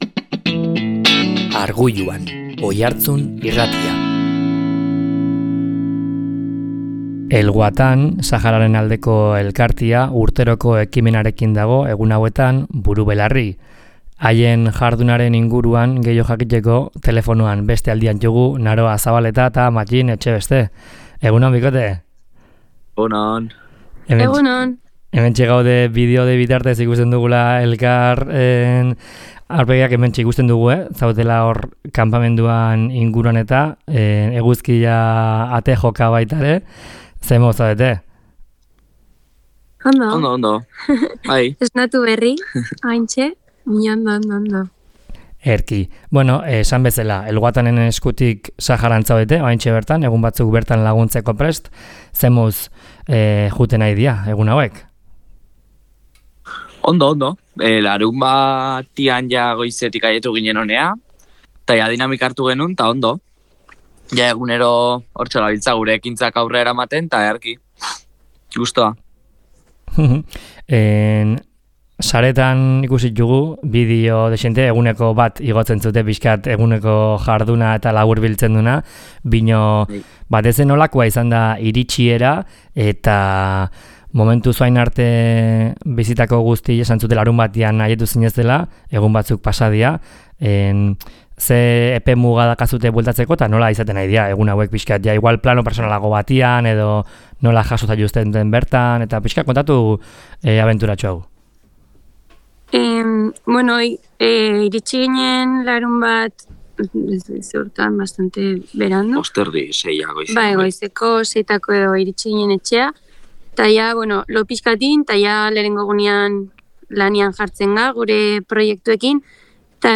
Saharatik zuzenean “El Watan” elkarteko kideekin – Oiartzun Irratia